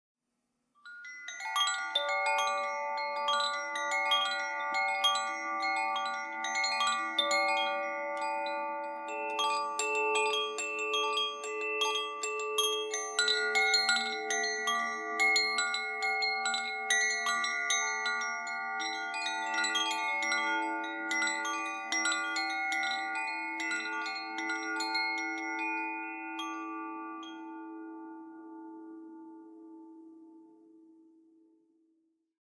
Meinl Sonic Energy Cosmic Bamboo Chime - 432 Hz/Aurora (Morgen) (CBCAURORA)
Die Meinl Sonic Energy Cosmic Bamboo Chimes erzeugen weiche, nachklingende Töne, die Ihren Raum mit ruhigen Klängen erfüllen.
Im Inneren befinden sich ein Pendel und mehrere Metallstäbe, die auf bestimmte Tonhöhen gestimmt sind.